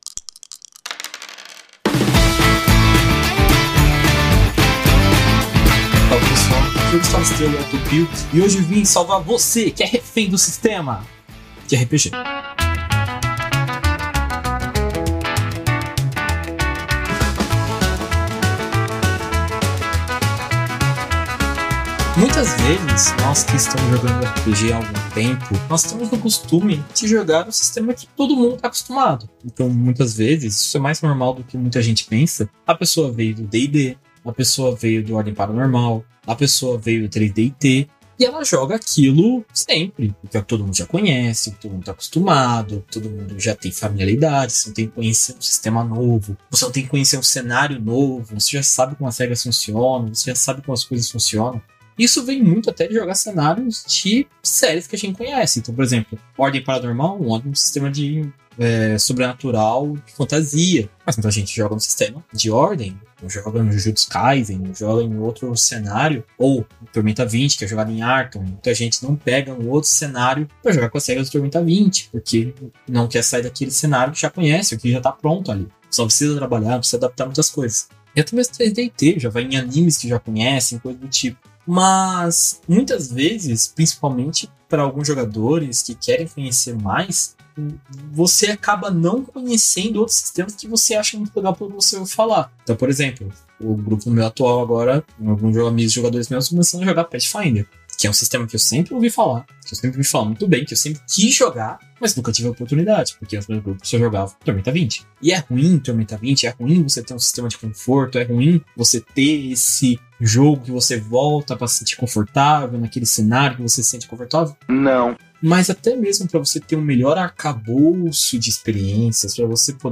O Dicas de RPG é um podcast semanal no formato de pílula que todo domingo vai chegar no seu feed.